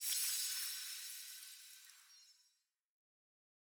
ac-sfx-mgx-gold-flair.ogg